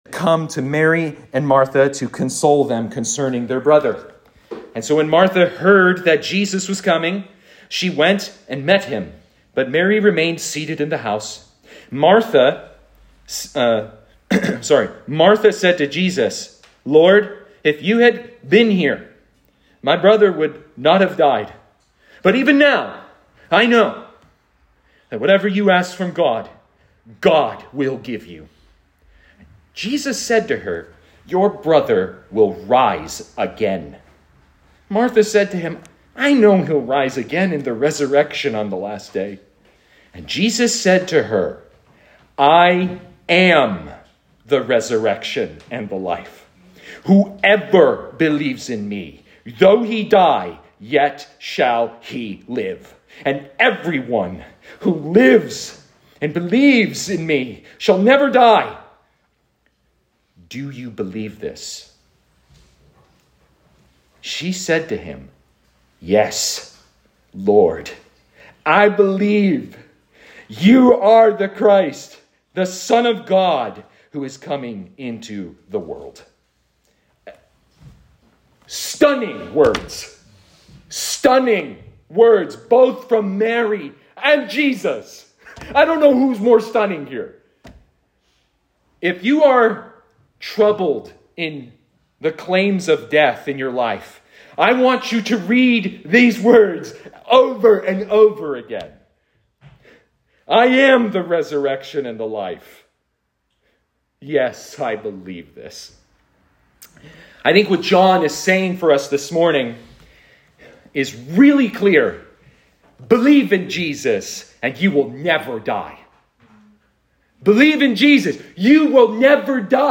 All Sermons “Do you believe this?”